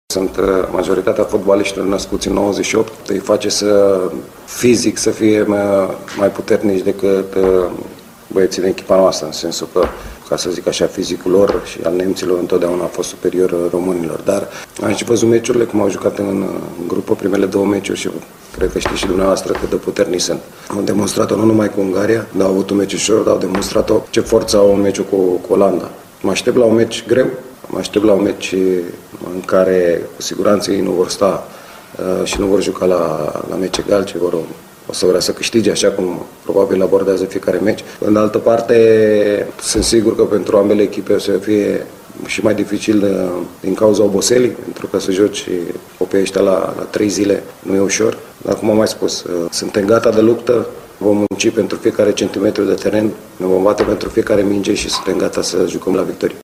Selecționerul Adrian Mutu a vorbit, de asemenea, despre adversar și despre meci: